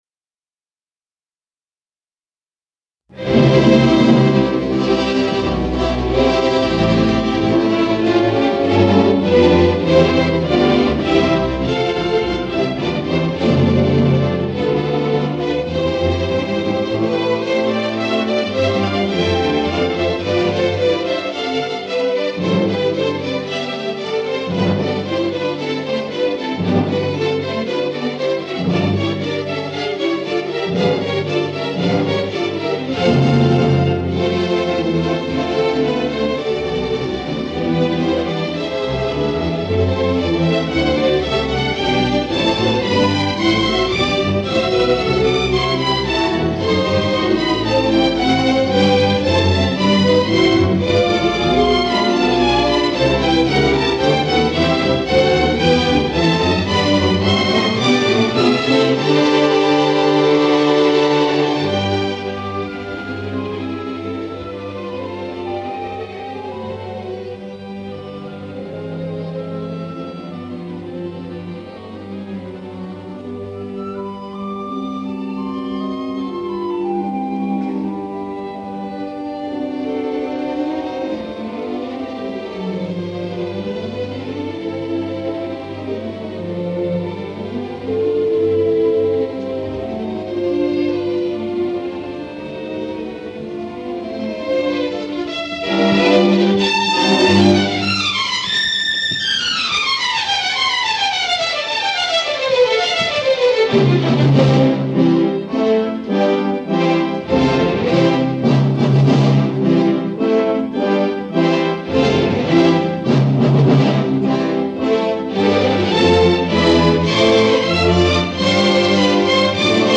先由管弦乐奏出《名歌手动机》。再由长笛开始，奏出表情丰富的柔和旋律。
这时乐曲变成E大调，小提琴唱出“爱的动机”。